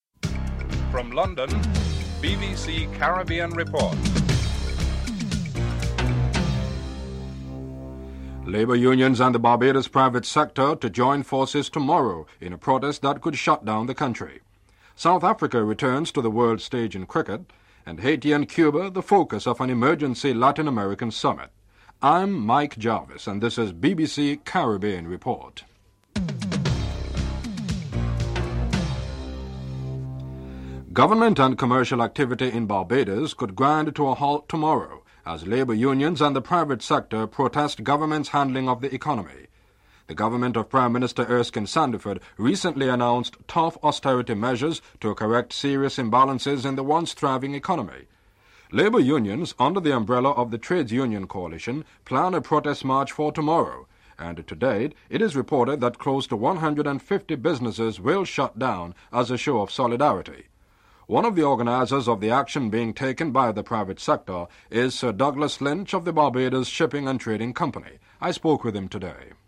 1. Headlines (00:00-00:29)